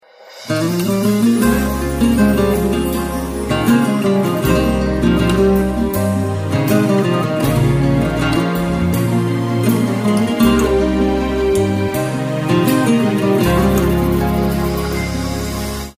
(بدون کلام)